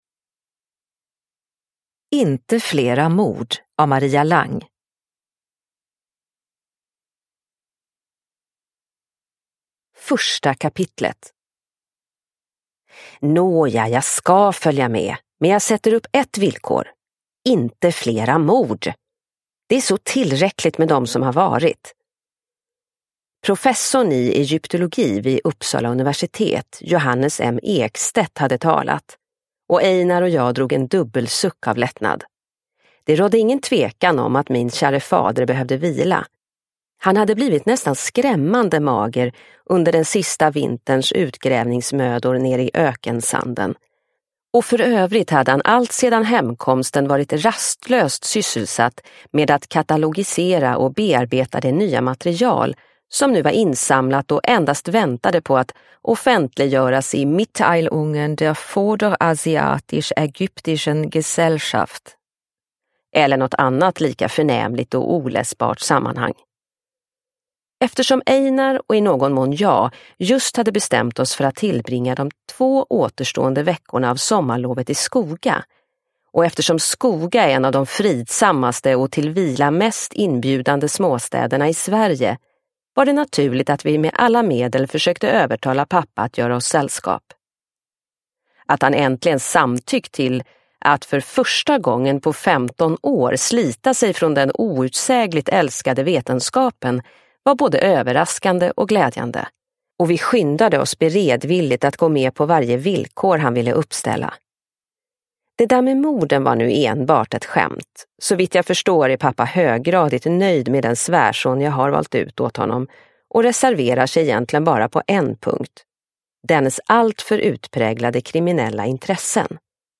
Inte flera mord! – Ljudbok – Laddas ner